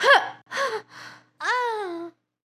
dead.wav